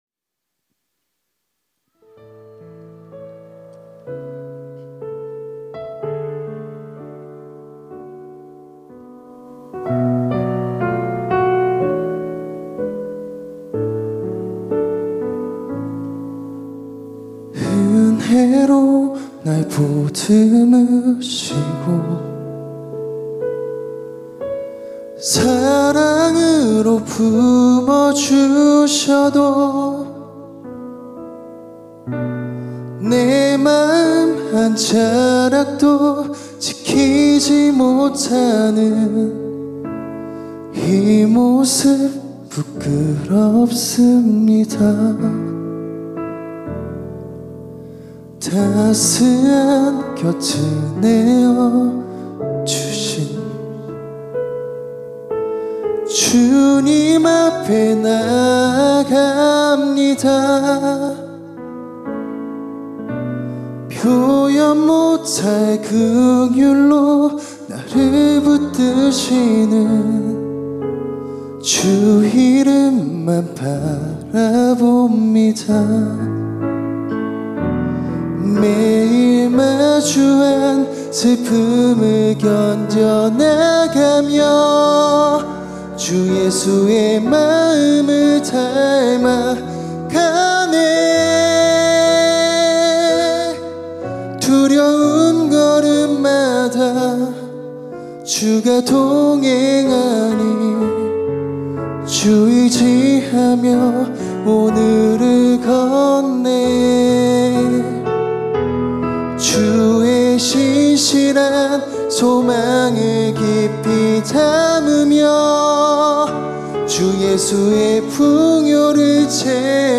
특송과 특주 - 깊어진 삶을 주께